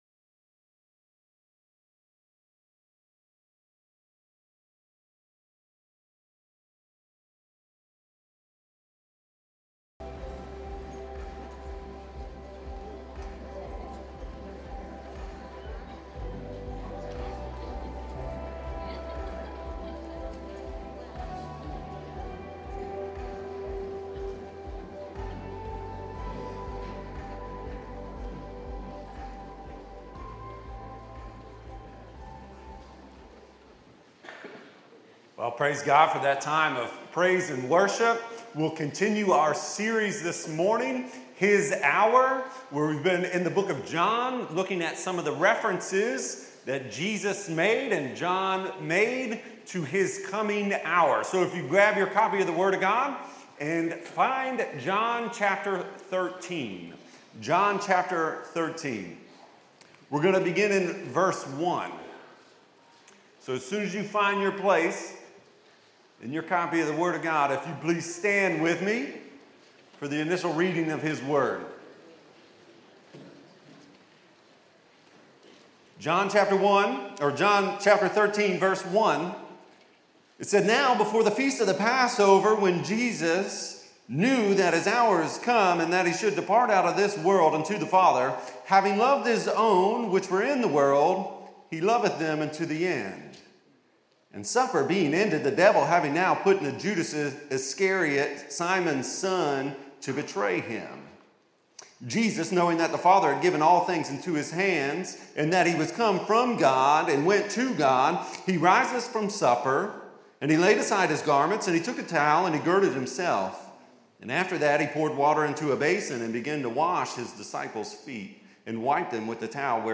Sermons | Battlefield Baptist Church